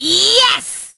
penny_kill_vo_05.ogg